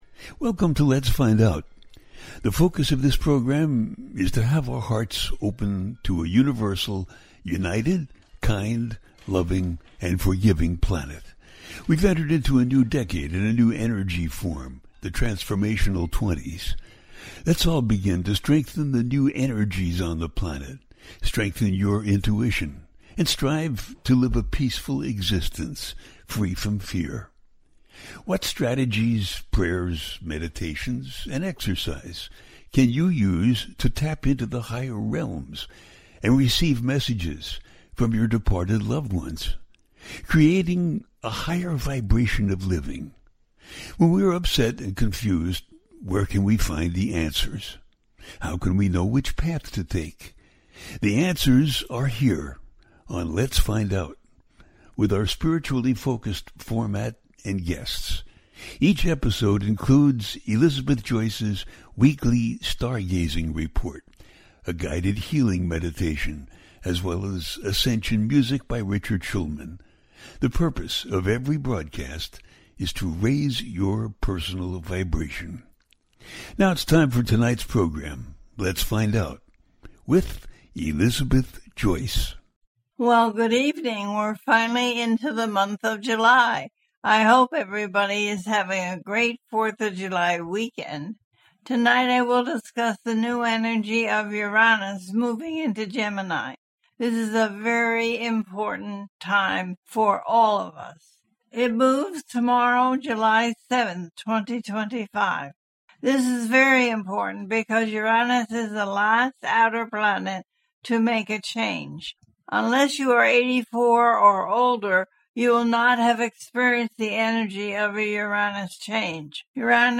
In July Uranus Moves into Gemini, The Capricorn Full Moon - A teaching show
The listener can call in to ask a question on the air.
Each show ends with a guided meditation.